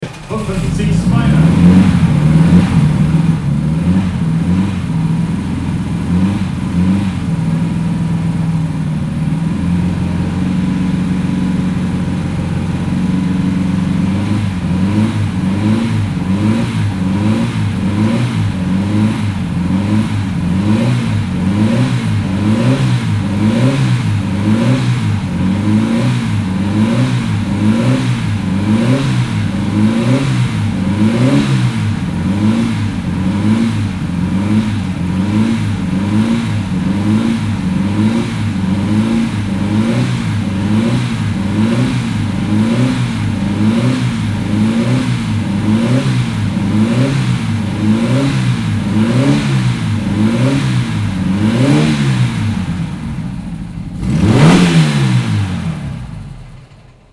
Porsche Sound Night 2017 - a loud night at the museum (Event Articles)